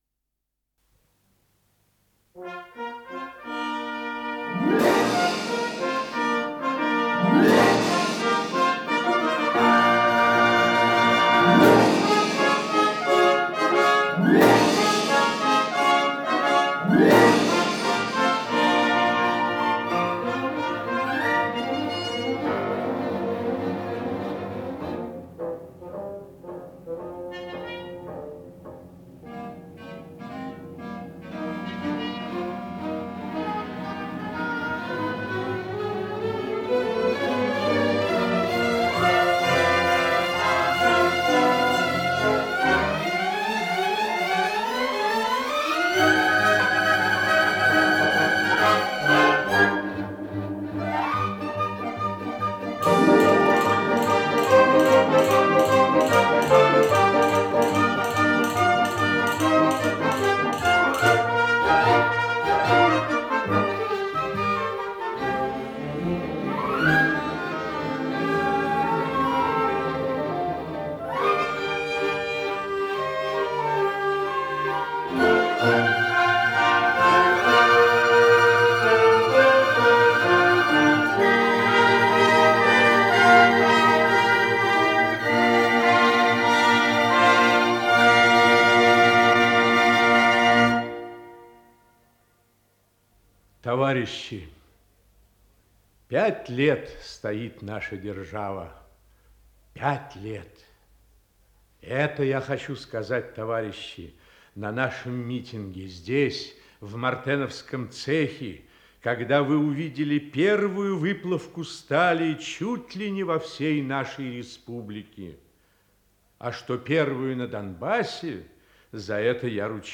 Исполнитель: Артисты Киевского театра русской драмы им. Леси Украинки
Радиопостановка